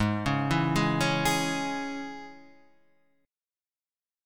G# Suspended 4th Sharp 5th